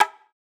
TS Perc_1.wav